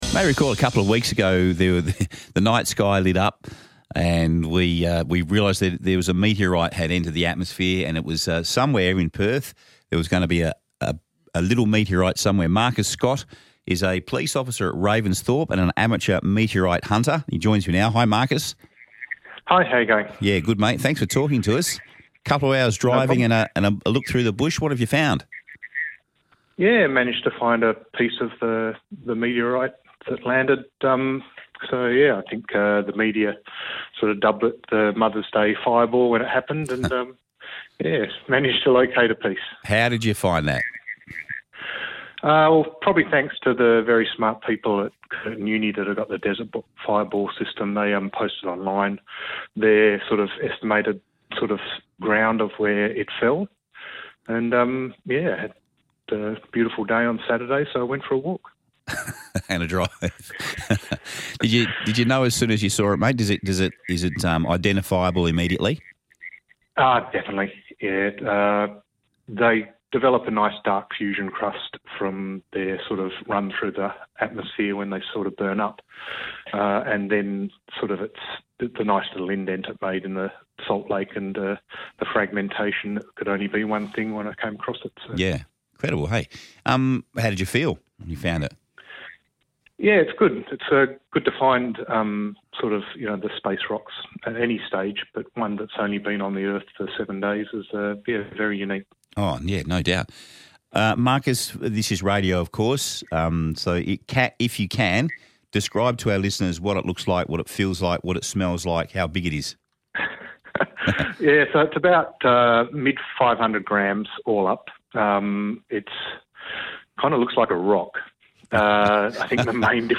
Interview
MC_Interview.mp3